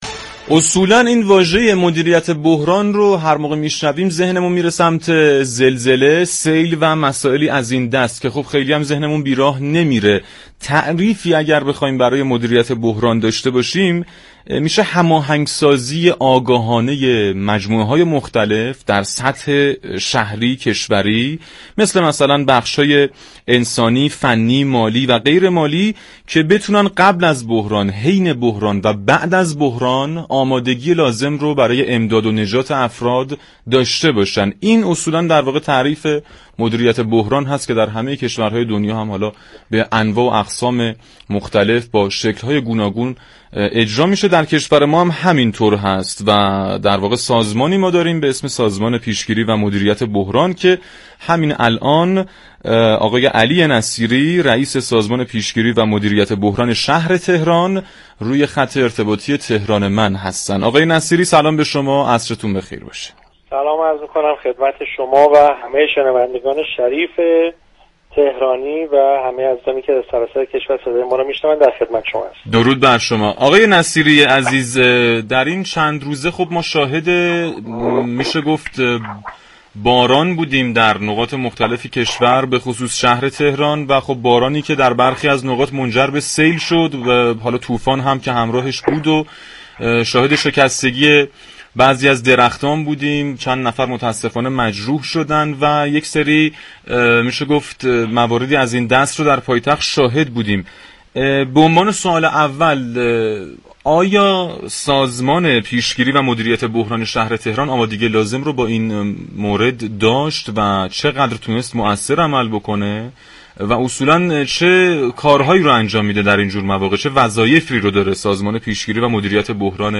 علی نصیری رئیس سازمان پیشگیری و مدیریت بحران شهر تهران در گفت و گو با «تهران من» اظهار داشت